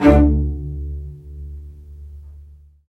Index of /90_sSampleCDs/Roland LCDP13 String Sections/STR_Vcs Marc&Piz/STR_Vcs Odd Marc